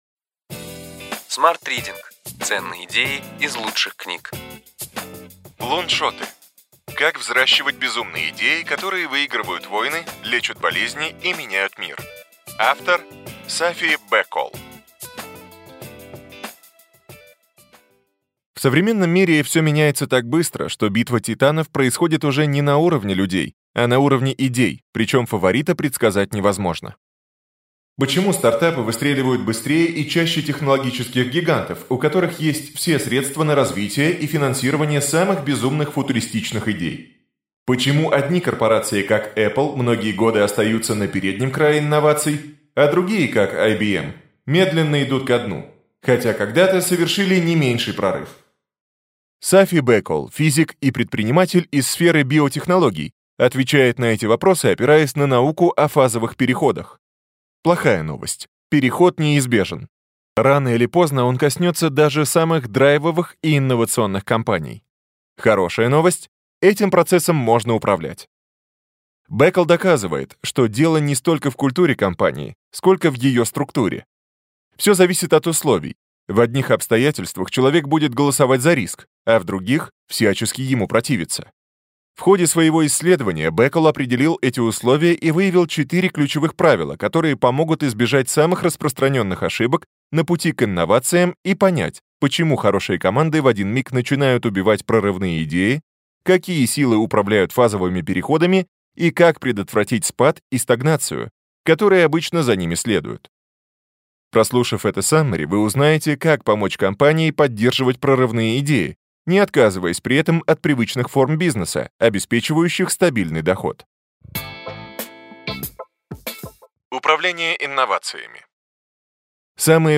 Аудиокнига Ключевые идеи книги: Луншоты. Как взращивать безумные идеи, которые выигрывают войны, лечат болезни и меняют мир.